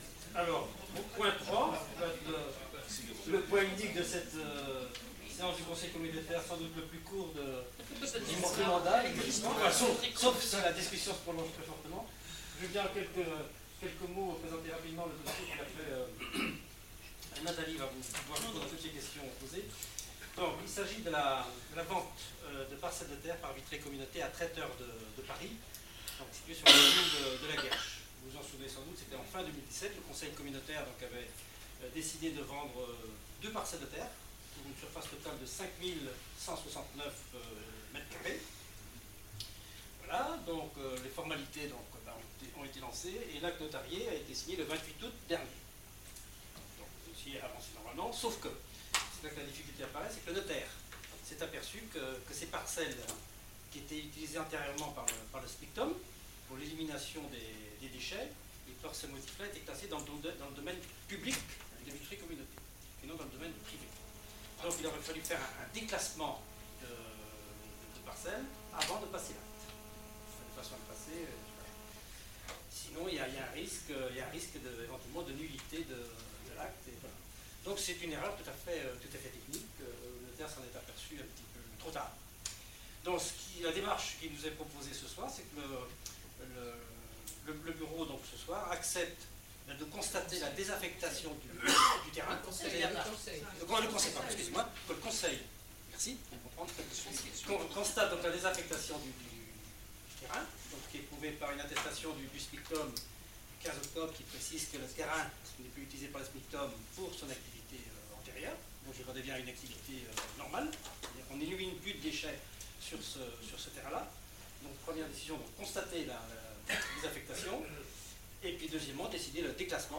Audio du conseil communautaire du 22 octobre 2018